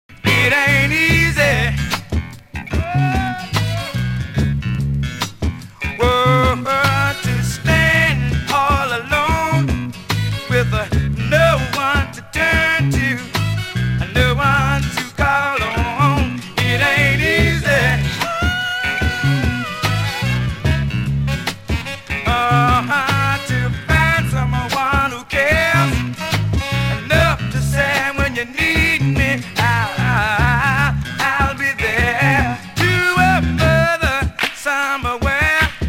(税込￥2420)   FUNK